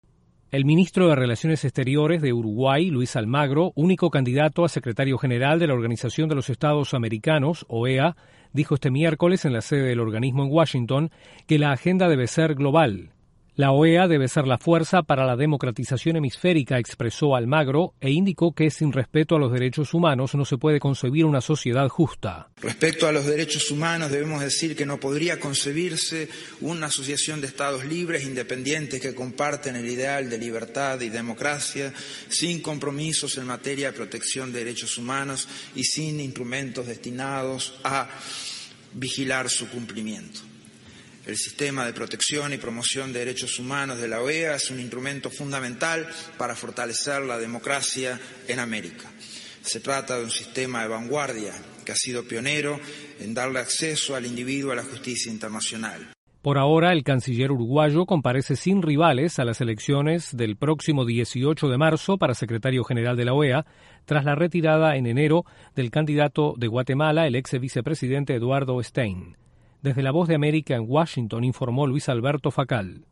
Desde la Voz de América informa